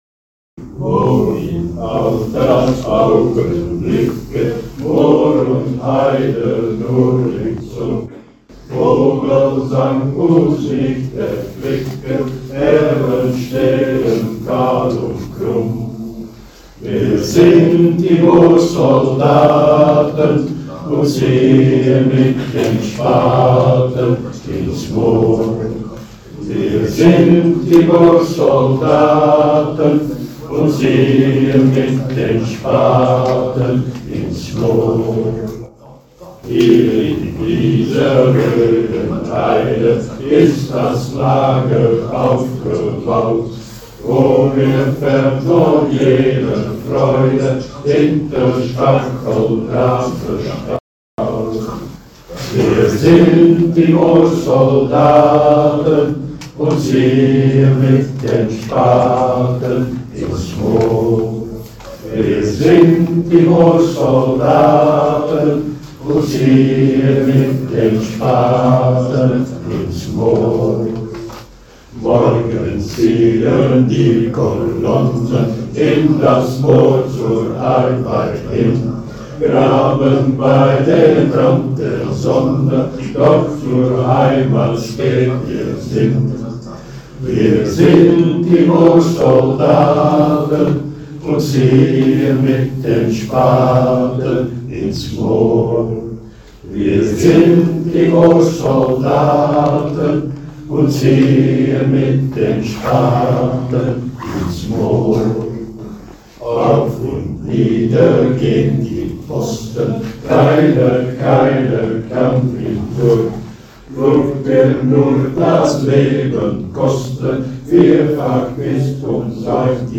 tijdens de herdenking te Breendonk
Live opname van "Die Moorsoldaten"
Breendonk 7 dec. 2024